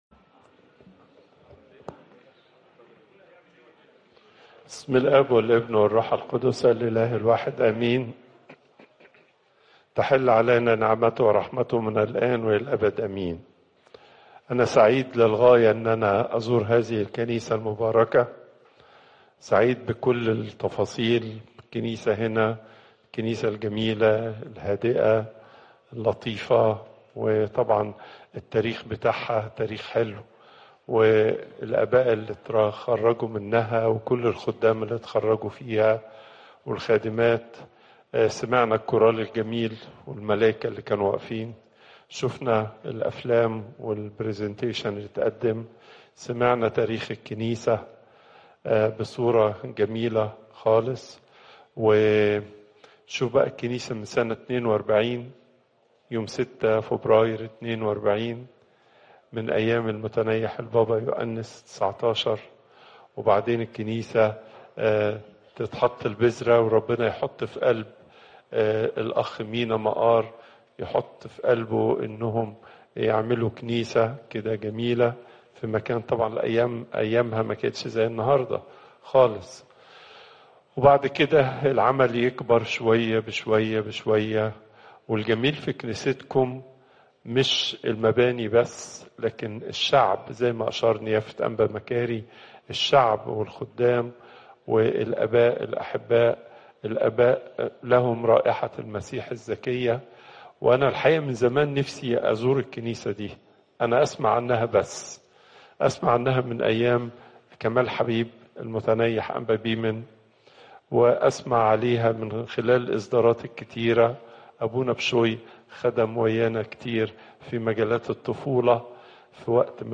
المحاضرة الأسبوعية لقداسة البابا تواضروس الثاني